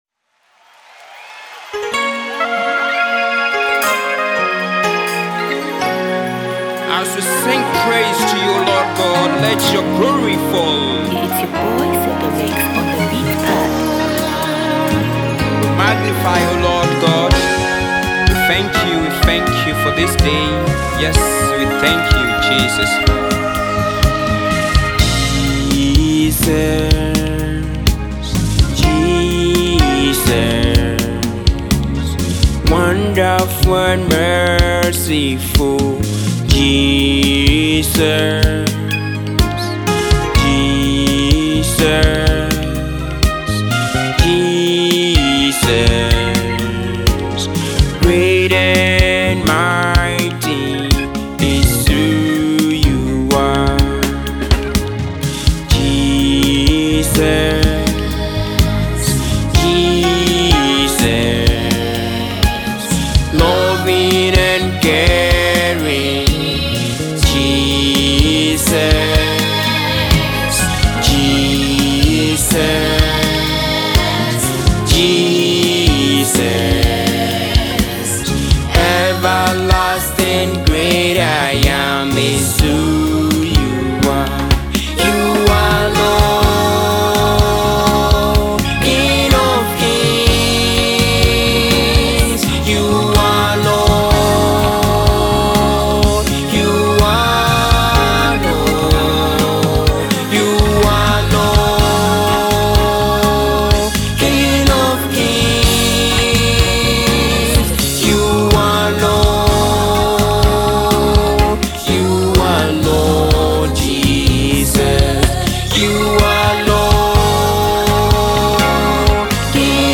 Gospel
beautiful adoration song